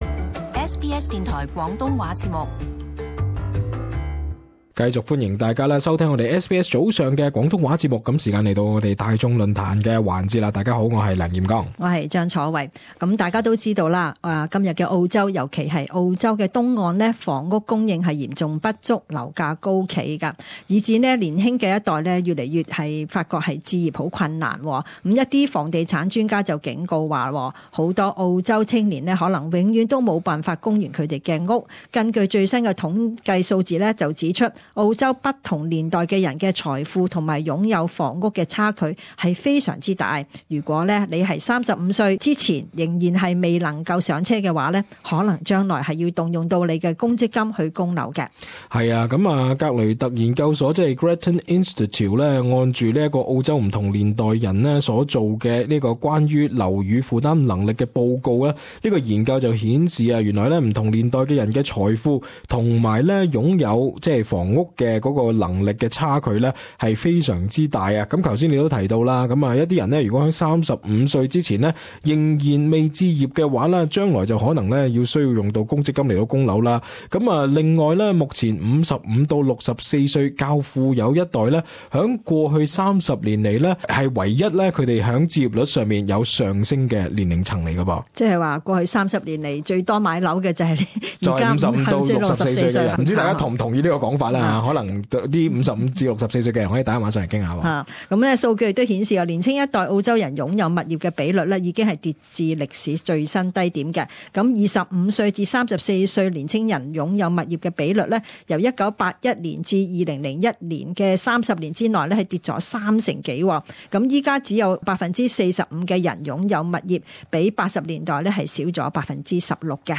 與聽眾朋友討論：到底大家是否覺得自己或下一代，供樓或置業壓力太大？